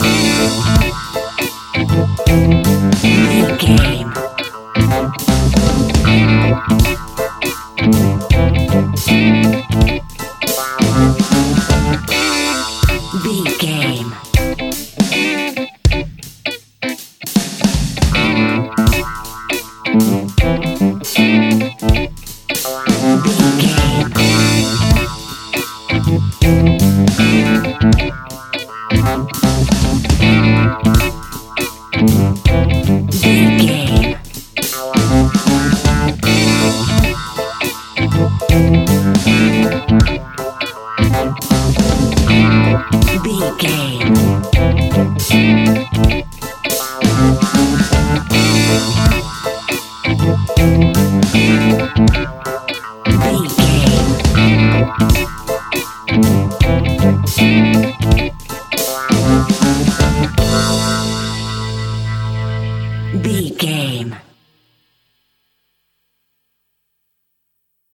Aeolian/Minor
Slow
dub
laid back
chilled
off beat
drums
skank guitar
hammond organ
percussion
horns